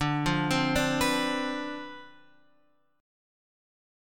D Minor 13th